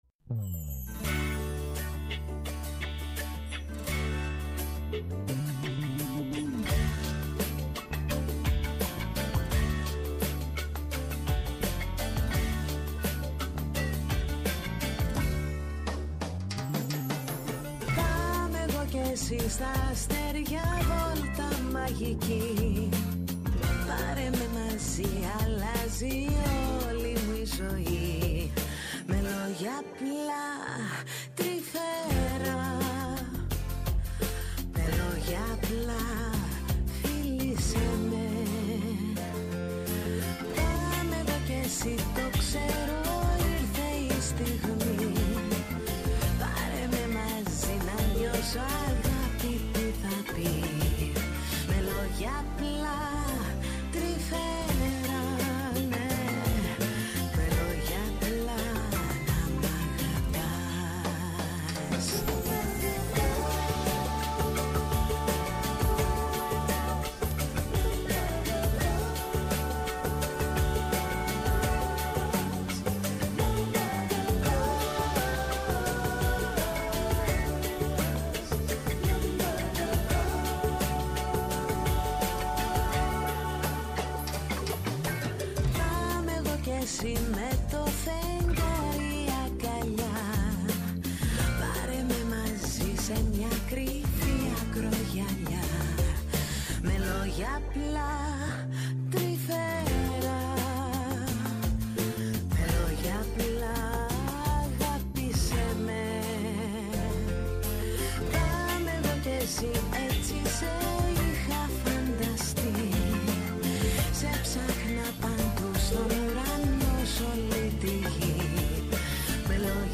ΔΕΥΤΕΡΟ ΠΡΟΓΡΑΜΜΑ Μουσική Συνεντεύξεις